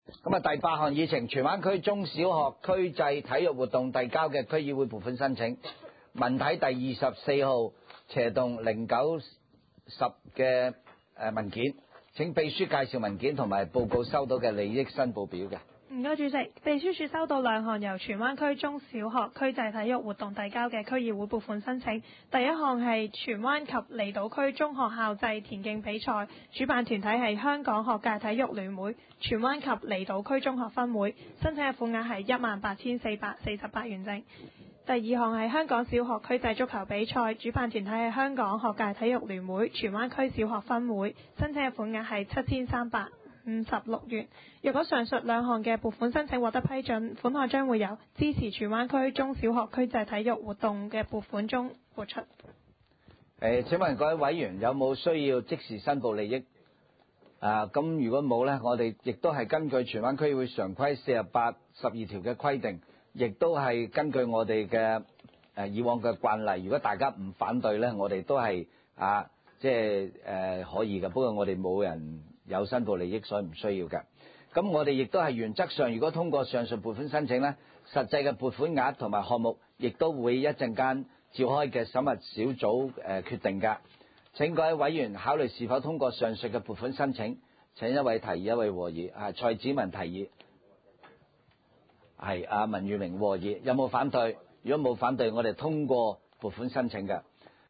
文娛康樂及體育委員會第十二次會議
荃灣民政事務處會議廳